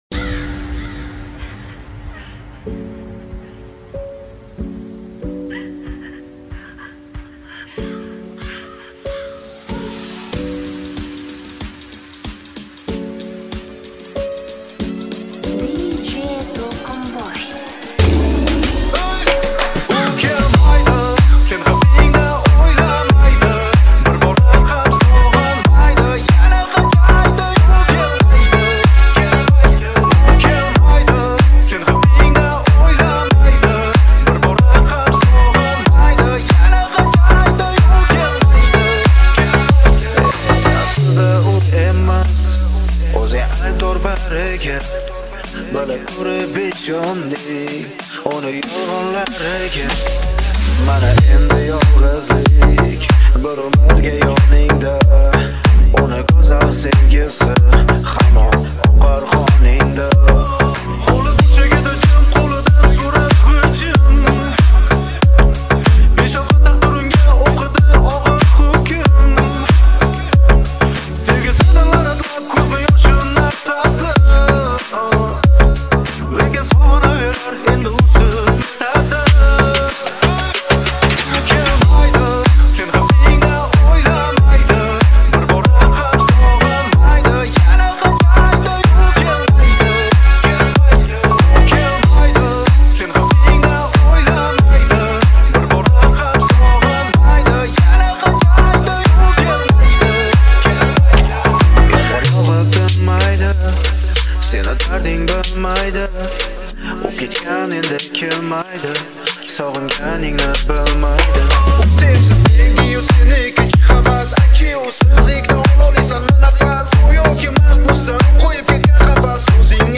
Узбекский песни